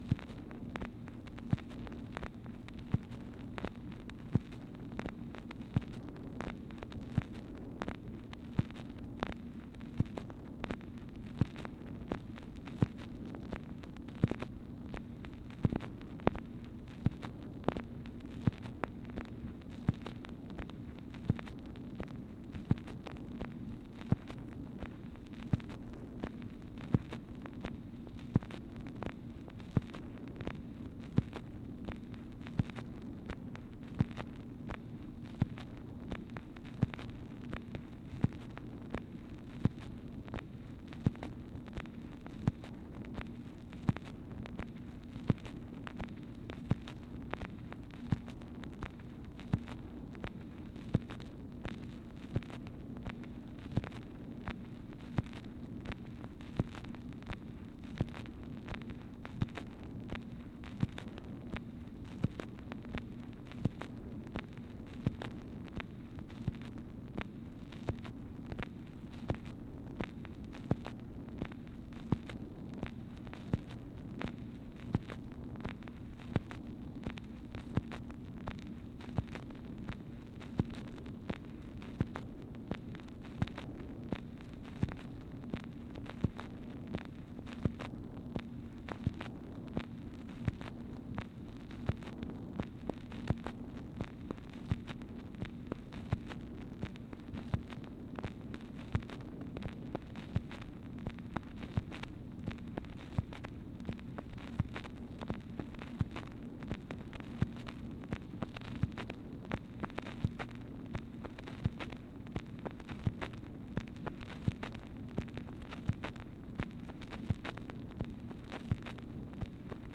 MACHINE NOISE, March 20, 1964
Secret White House Tapes | Lyndon B. Johnson Presidency